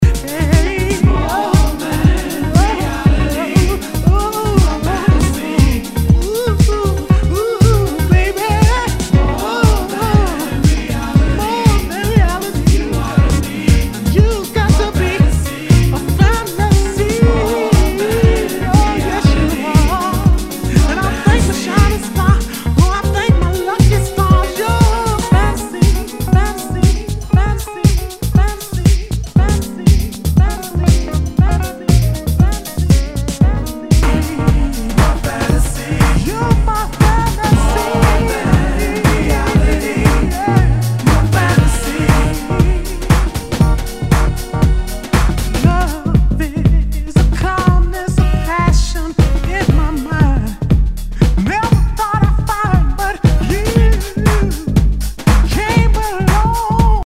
HOUSE/TECHNO/ELECTRO
ディープ・ヴォーカル・ハウス・クラシック！